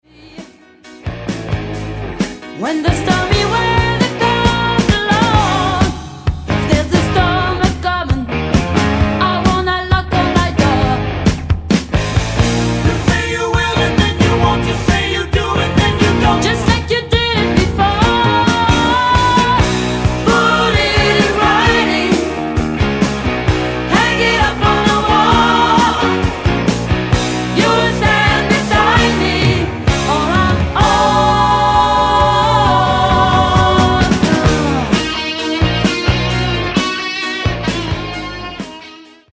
LightMellow